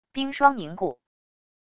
冰霜凝固wav音频